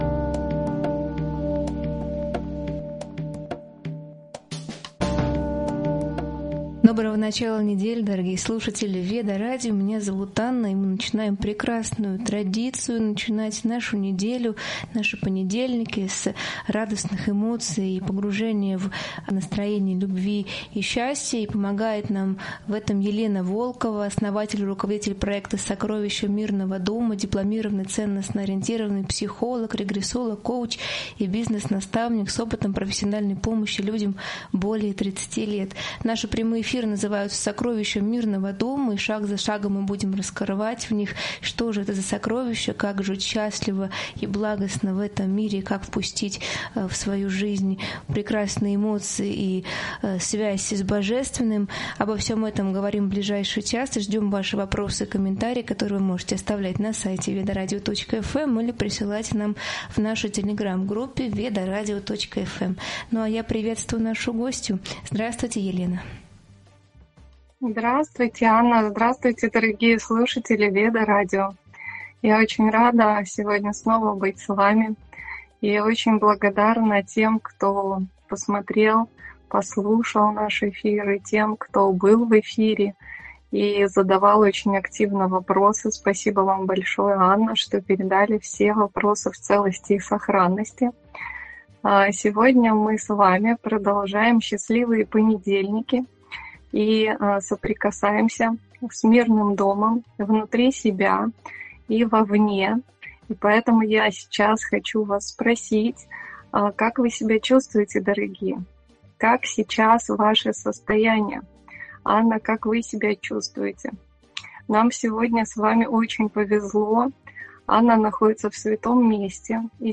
Темы эфира 01:06 — Эфир называется «Сокровища мирного дома», где обсуждаются способы впустить в жизнь любовь и прекрасные эмоции. 01:06 — Зрителей приглашают задавать вопросы через сайт или Telegram-группу радиостанции.